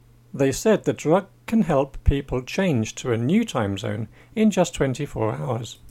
DICTATION 3